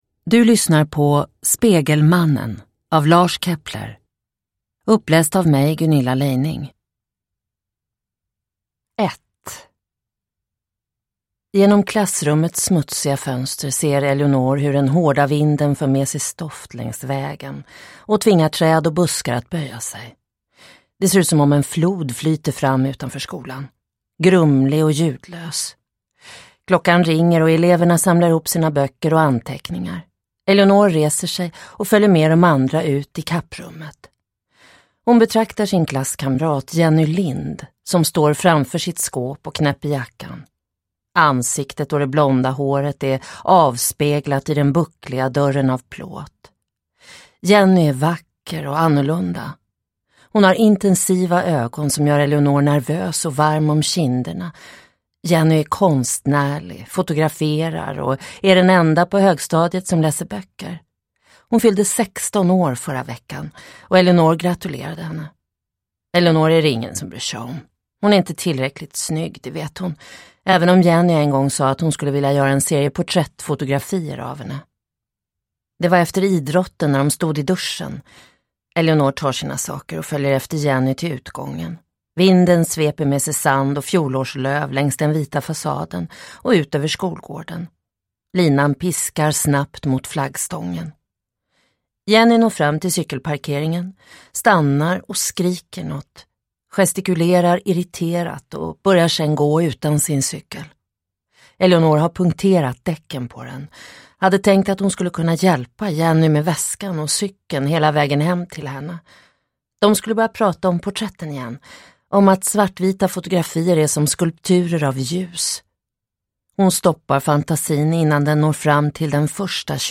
Spegelmannen – Ljudbok – Laddas ner